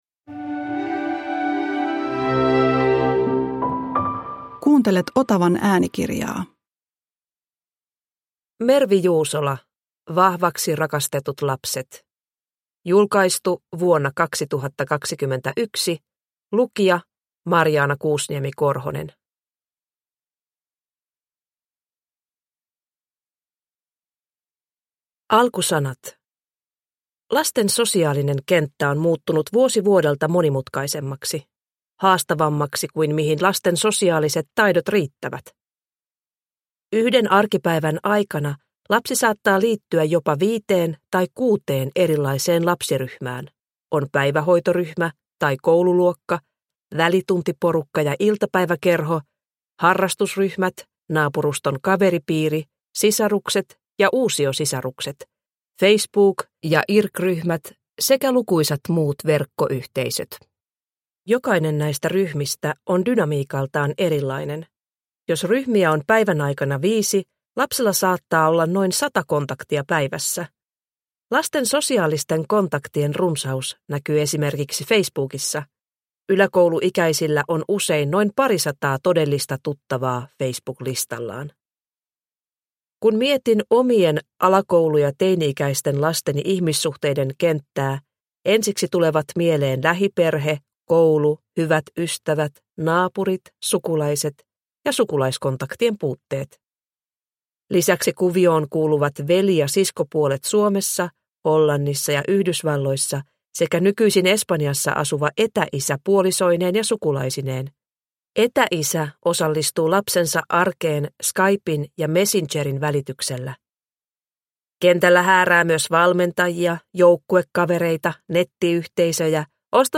Vahvaksi rakastetut lapset – Ljudbok – Laddas ner